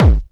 SINGLE HITS 0024.wav